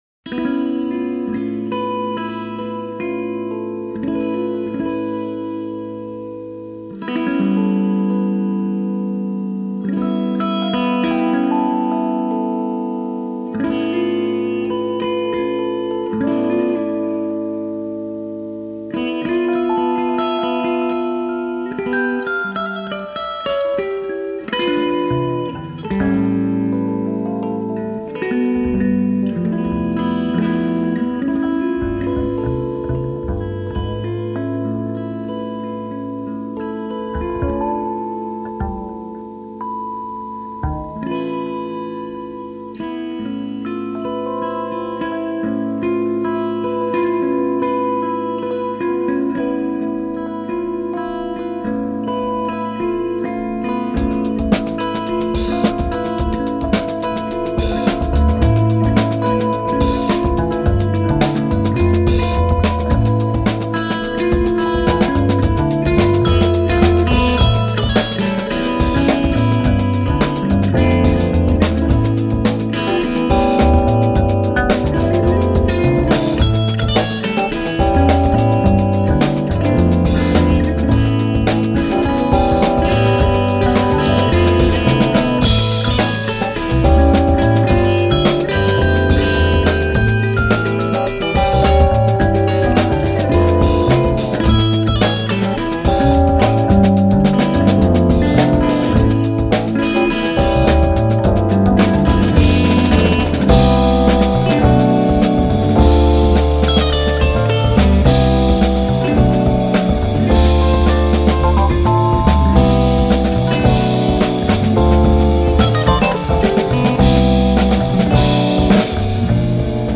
including this epic funk odyssey
Tags2000s 2005 funk Western US